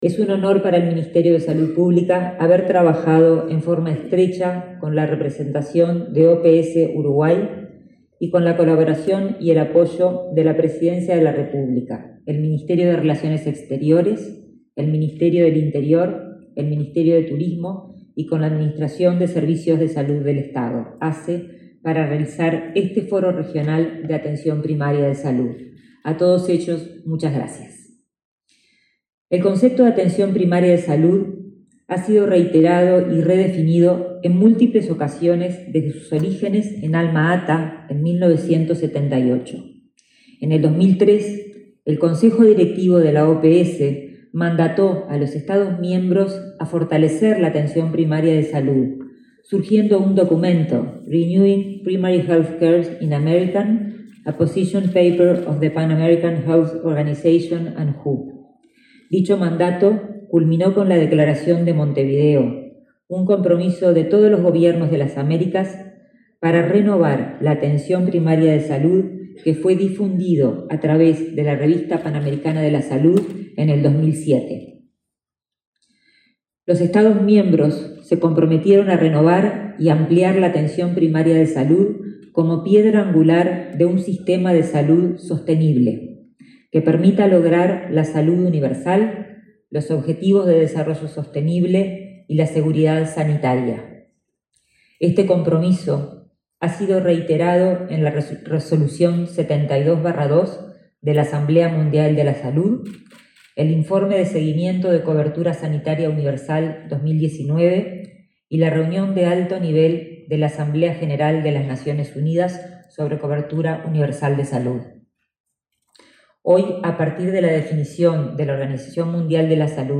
Palabras de la ministra de Salud Pública, Karina Rando
Este lunes 4, en Montevideo, la ministra de Salud Pública, Karina Rando, participó en el lanzamiento de la Alianza para la Atención Primaria de Salud.